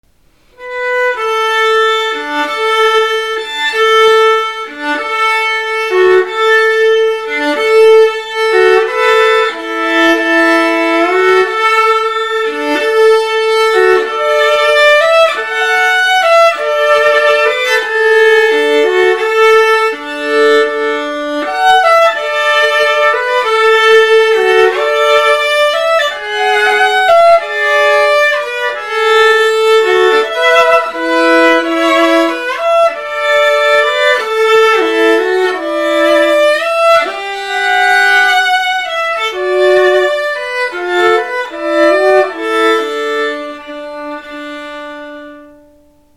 Komposition für Video, Tonspur und Bratsche
Zusätzlich zu „Diep in het bos“ und anschließendem Treffen mit den Künstlern wird Besuchern der Festival-Eröffnungs-Veranstaltung noch ein ganz besonderer Leckerbissen geboten: Eine Komposition zwischen altgälischer Melodie und Videoreproduktion, deren Titel nicht von ungefähr an ein geklontes Schaf gleichen Namens erinnert.